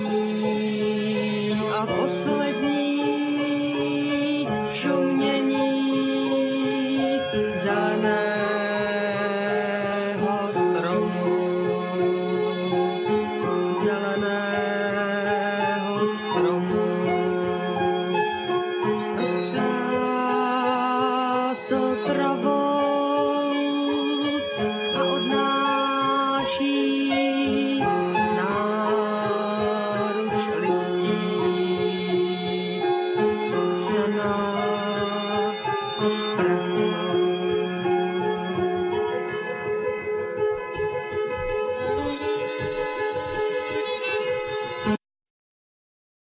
Piano,Vocal,Drum,Vibraphone,Glockenspiel
Violin,Piano,Vocal,Vibraphone,Glockenspiel